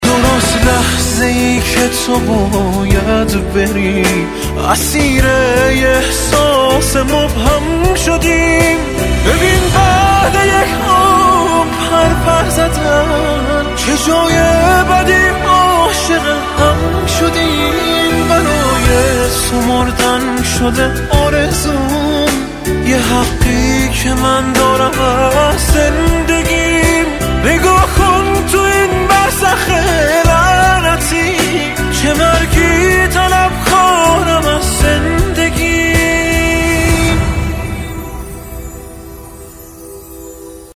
زنگ موبایل احساسی